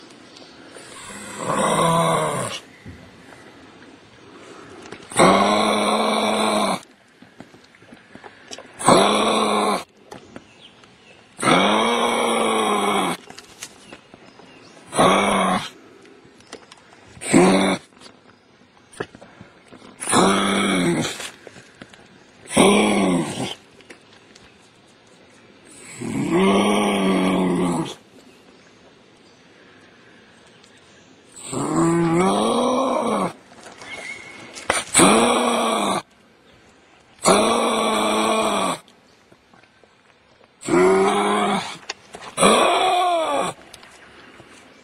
Звуки кенгуру
Рычание разъяренного кенгуру